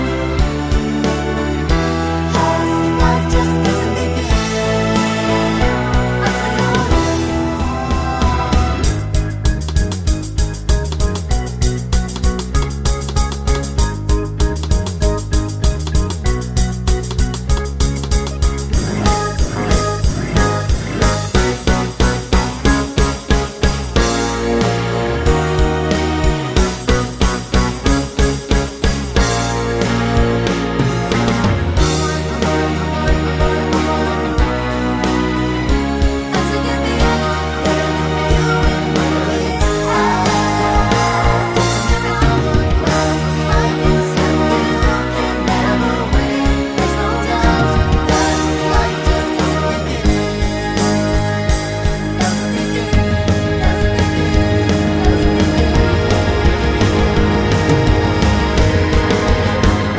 TV Length Soundtracks 1:58 Buy £1.50